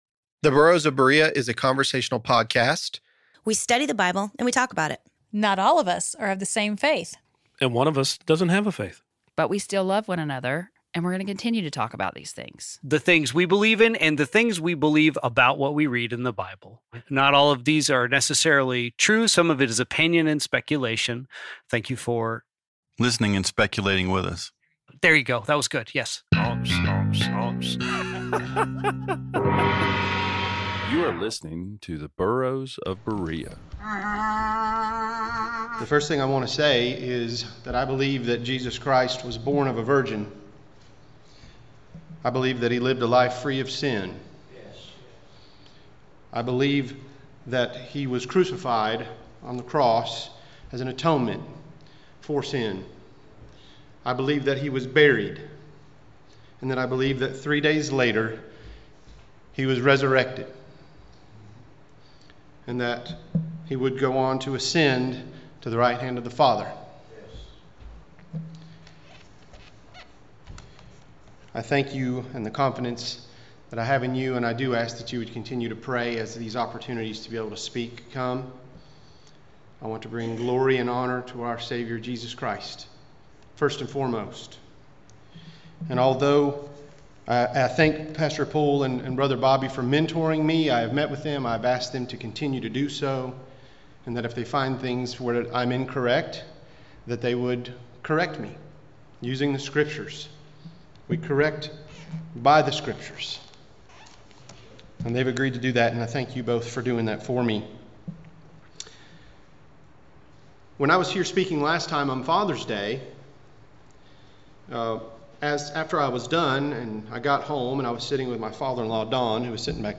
The Burros of Berea is a conversational podcast. We study the Bible and we talk about it.